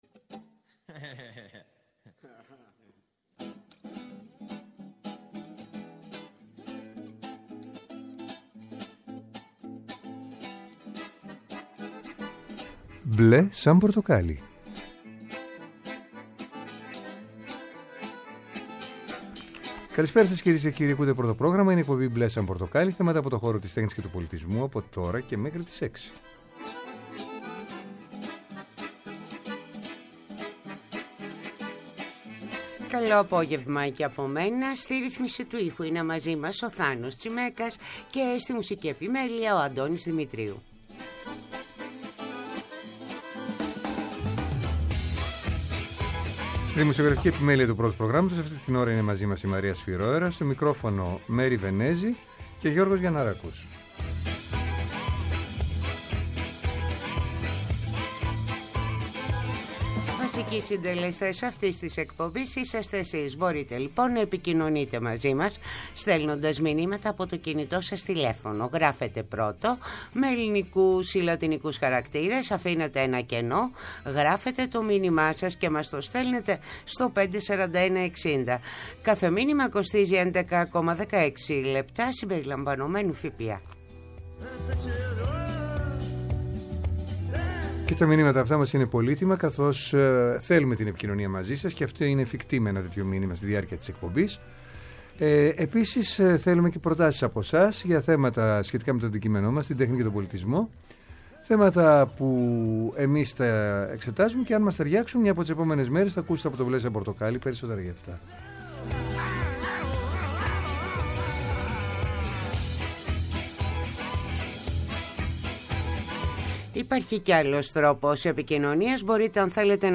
“Μπλε σαν Πορτοκάλ”ι. Θέατρο, κινηματογράφος, μουσική, χορός, εικαστικά, βιβλίο, κόμικς, αρχαιολογία, φιλοσοφία, αισθητική και ό,τι άλλο μπορεί να είναι τέχνη και πολιτισμός, καθημερινά από Δευτέρα έως Πέμπτη 5-6 το απόγευμα από το Πρώτο Πρόγραμμα. Μια εκπομπή με εκλεκτούς καλεσμένους, άποψη και επαφή με την επικαιρότητα. ΠΡΩΤΟ ΠΡΟΓΡΑΜΜΑ